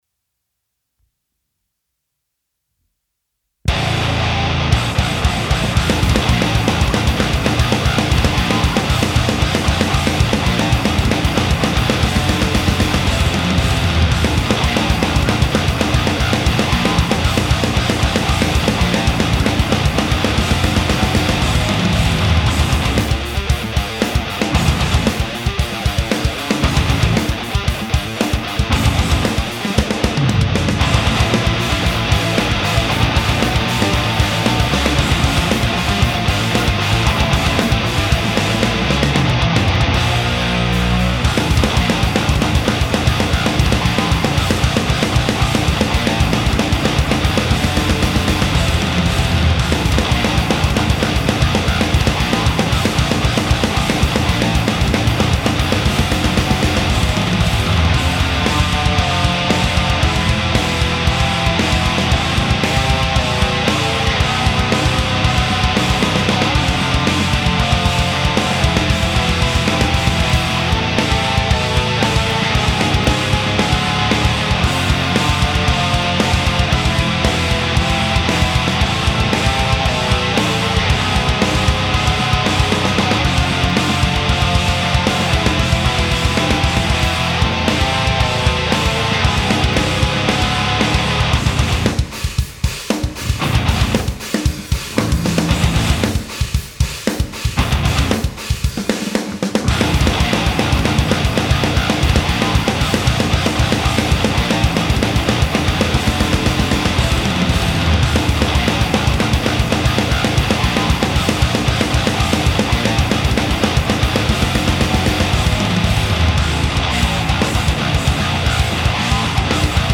Moin, hat noch keinen Songtitel und Gesang.